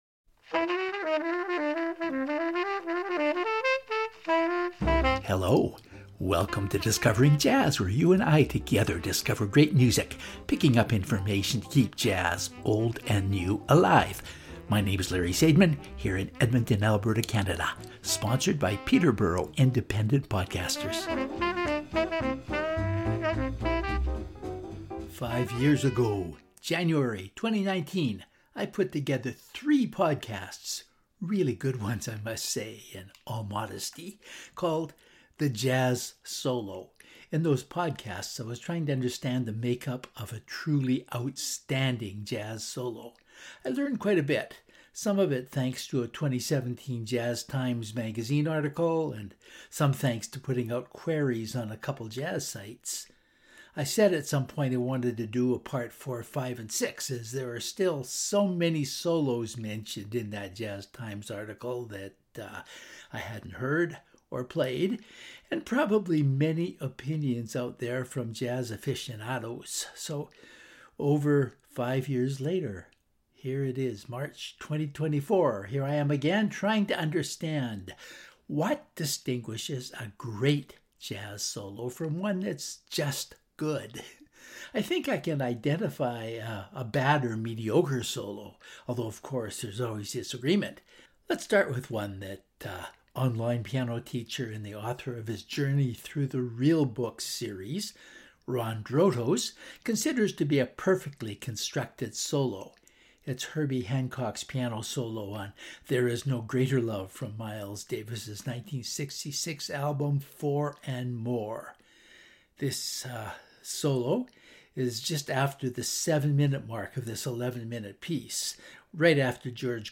This episode there are only seven tracks—and two of them are so long that I can’t play the whole thing.